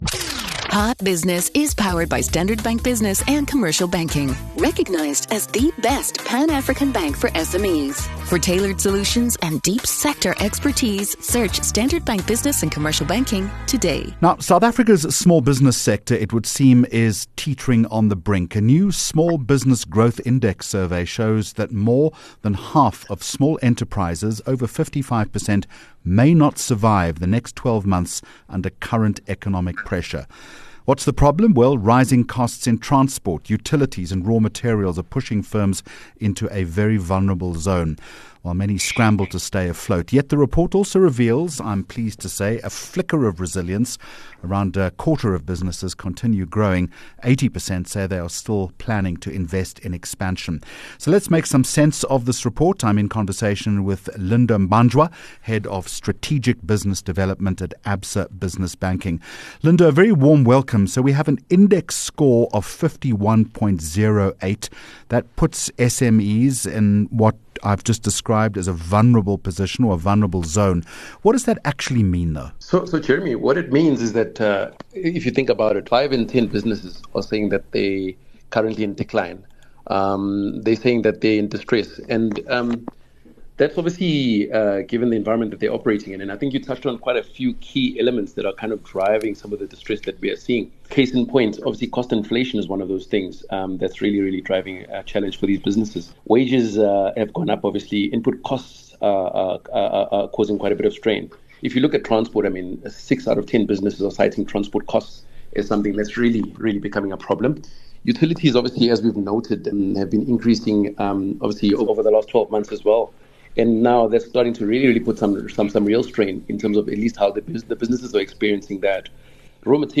7 Aug Hot Business Interview